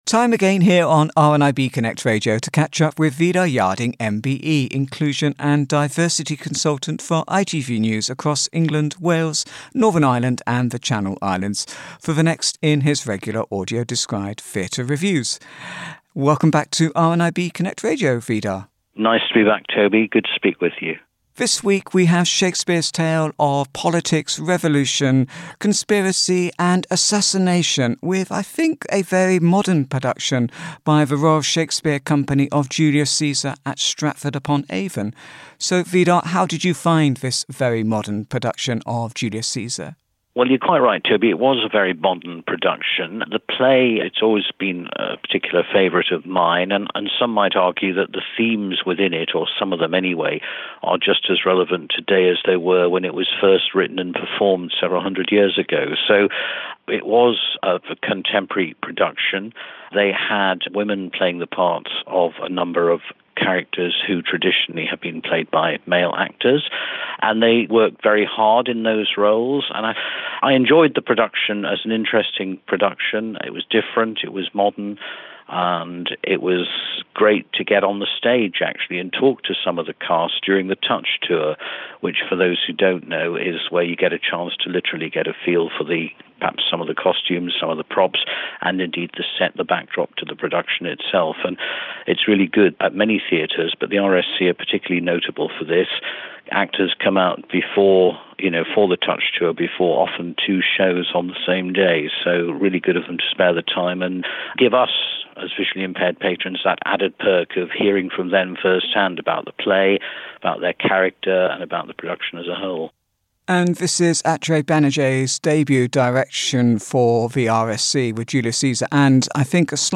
Audio Described Theatre Review